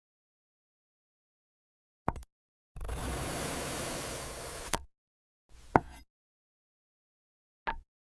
Glass Apple ASMR – clean sound effects free download
clean Mp3 Sound Effect Glass Apple ASMR – clean cuts, calm sounds.